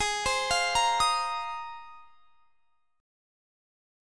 Listen to G#6add9 strummed